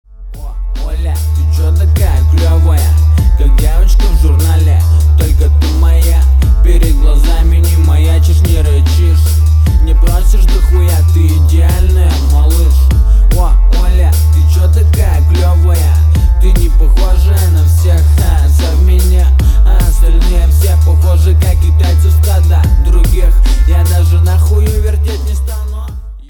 • Качество: 320, Stereo
красивые
речитатив